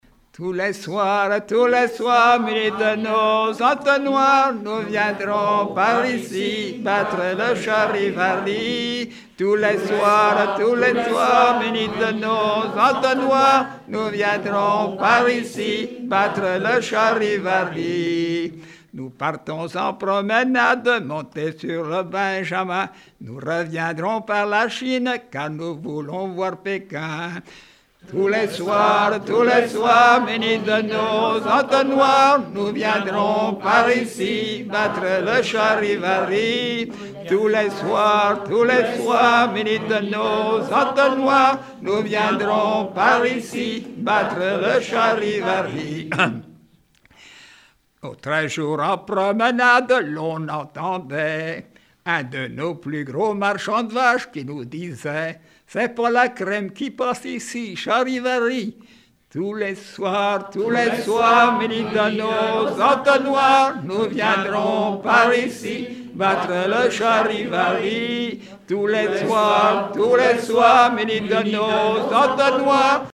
Chants brefs - De noces
Regroupement de chanteurs du canton
Pièce musicale inédite